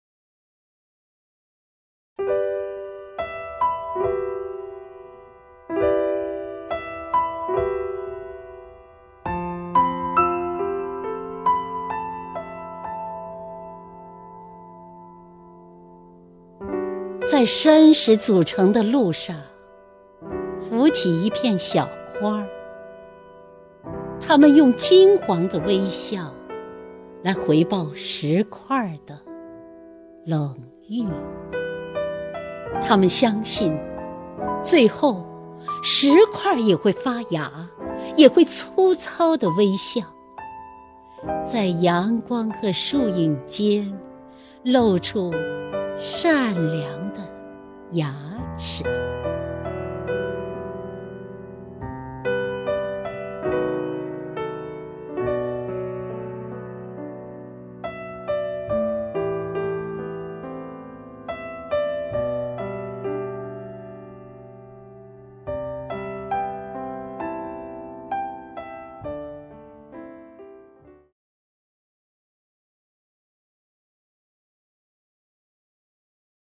首页 视听 名家朗诵欣赏 姚锡娟
姚锡娟朗诵：《小花的信念》(顾城)